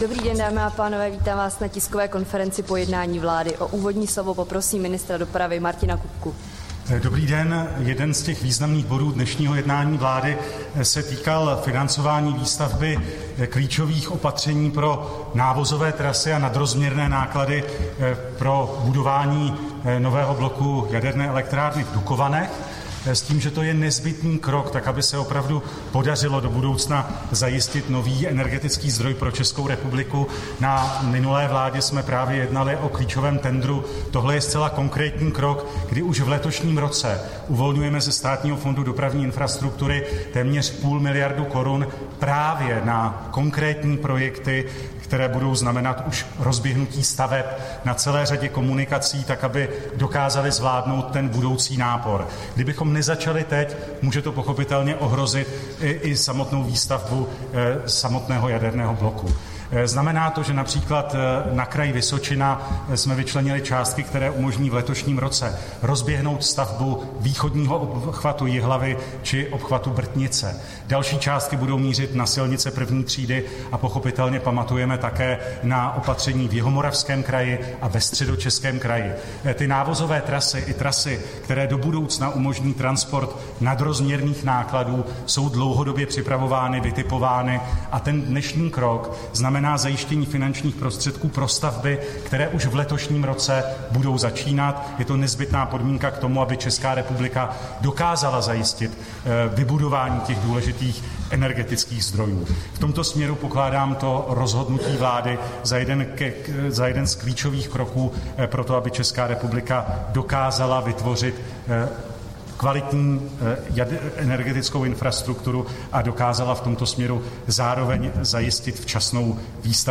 Tisková konference po jednání vlády, 7. února 2024